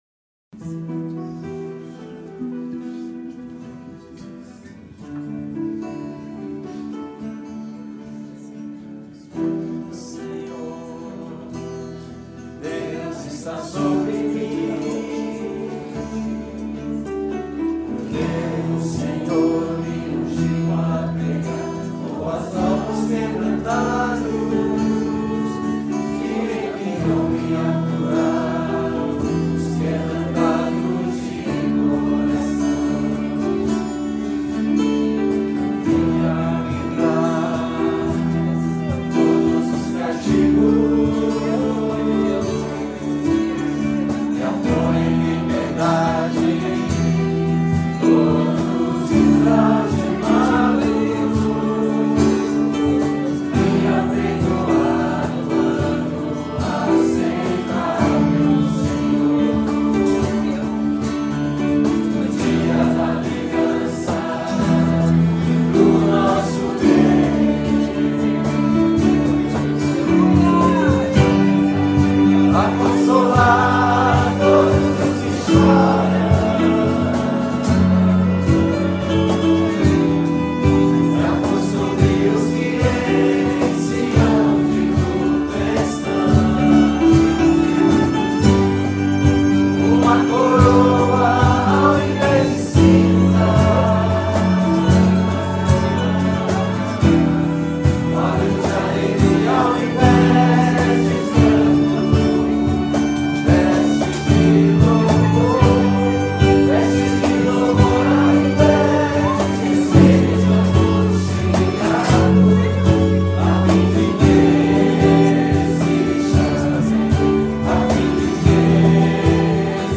Cânticos da convivência de jovens – Setembro/2016 | Cristo em Nós